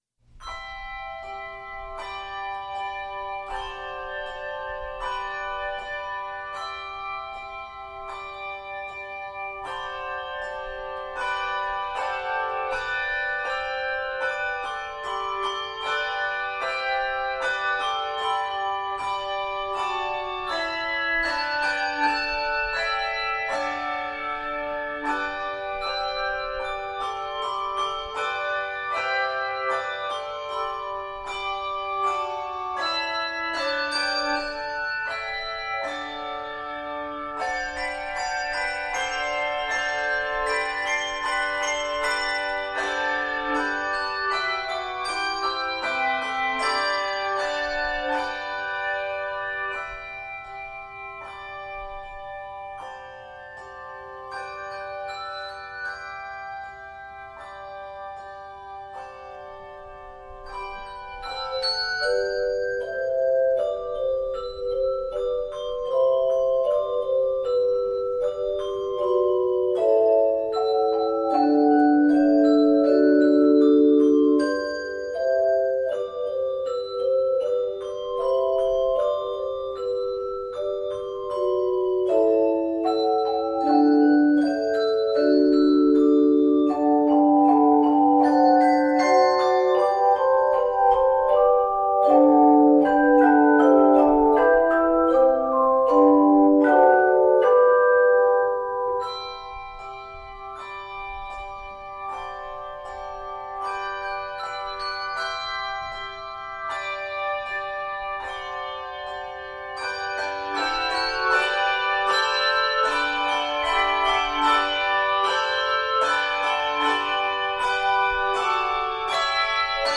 Key of G Major. 51 measures.